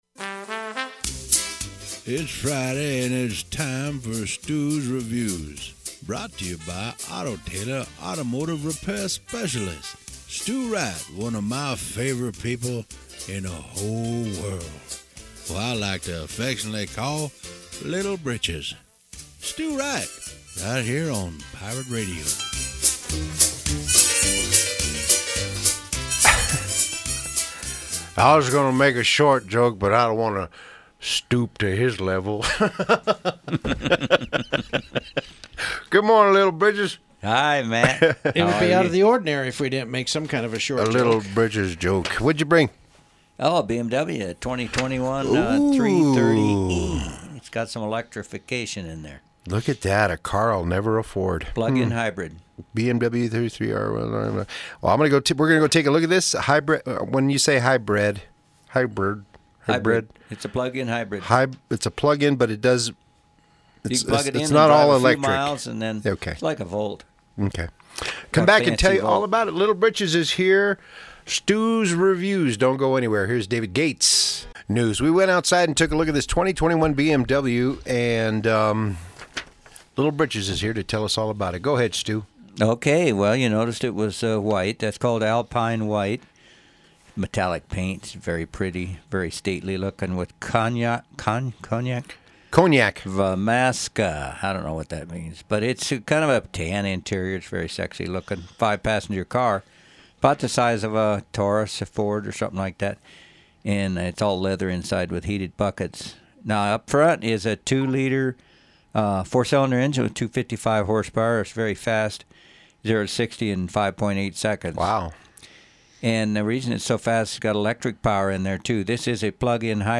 Pirate Radio review featuring DJ’s